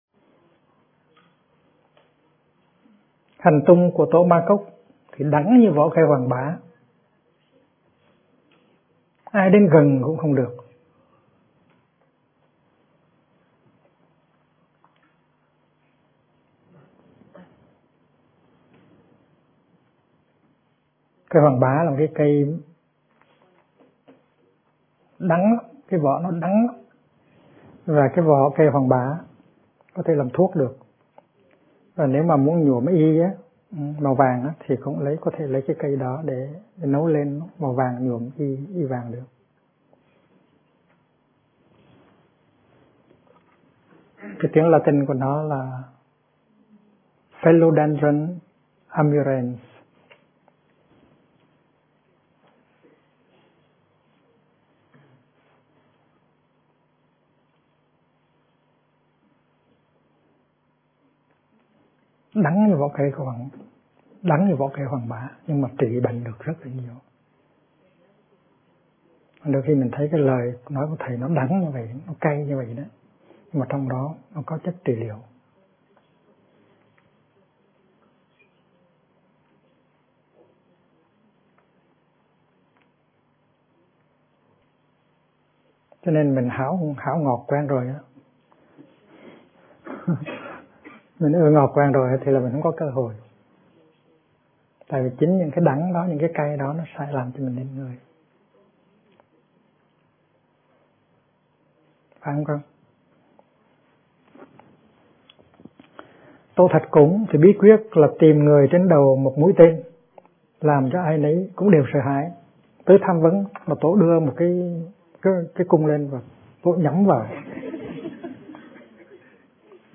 Kinh Giảng Sự Trao Truyền Của Chư Tổ - Thích Nhất Hạnh